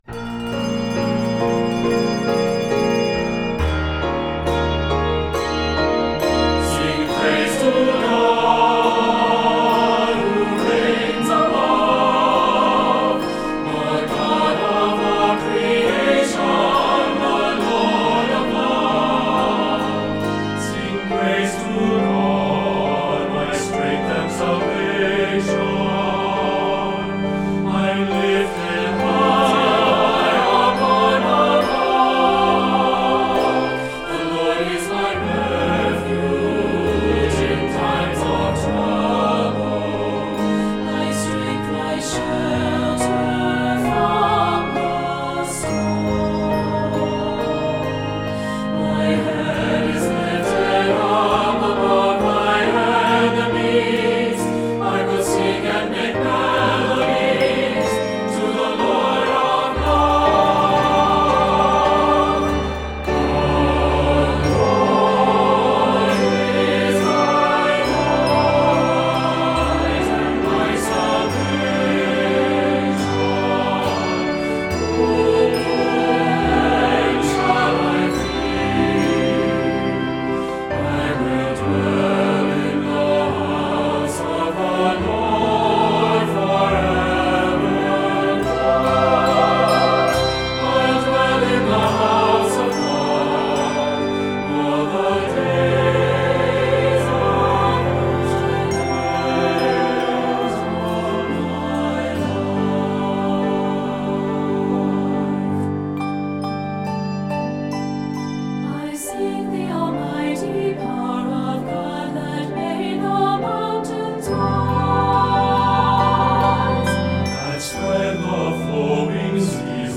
Keys of Ab Major and G Major.